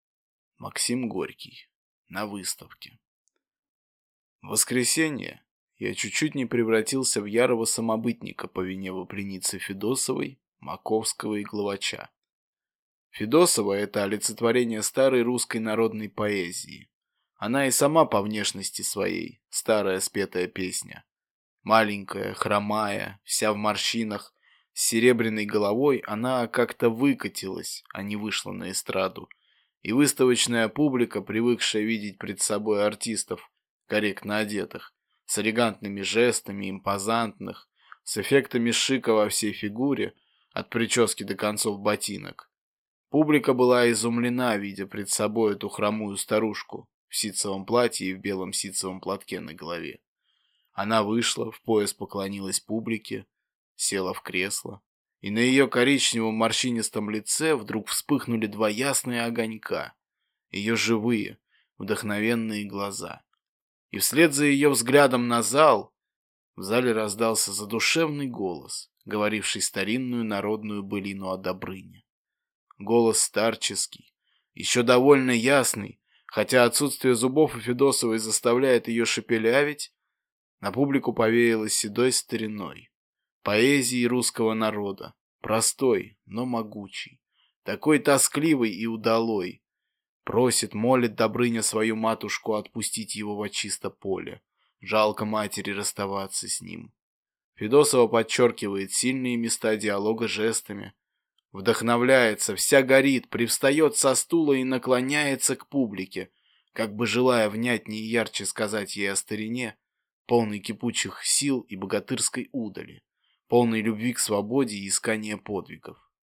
Aудиокнига На выставке